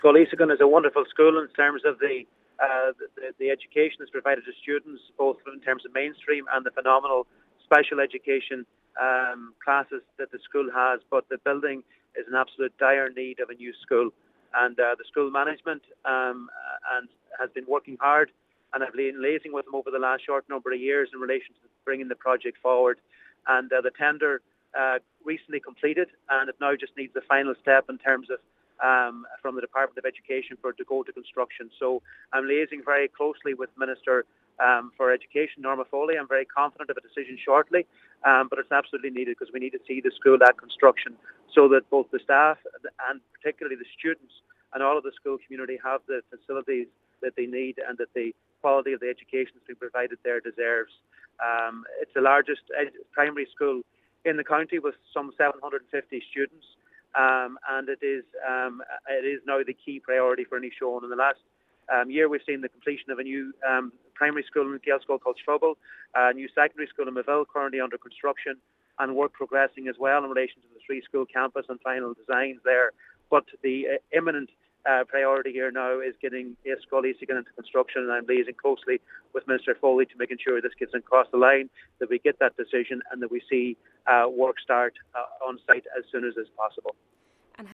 Minister McConalogue says while the education provided to the children is of top quality, the building is over 100 years old: